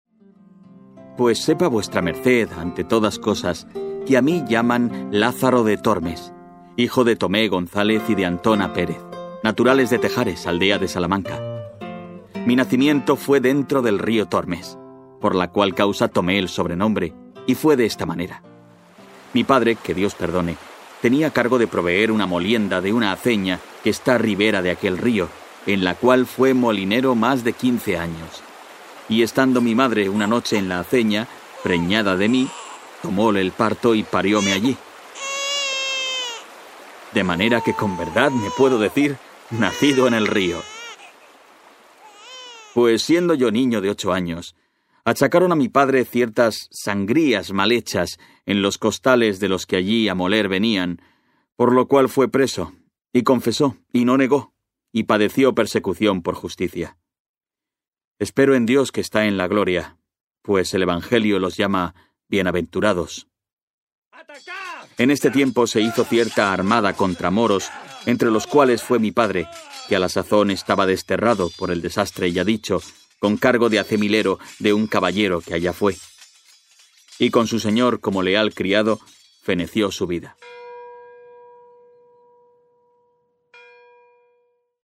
Narration
Neumann U-87, Pro Tools, Audition, Nuendo, Audient & UAudio interfaces.
ConversationalWarmBrightConfidentCharismaticUpbeat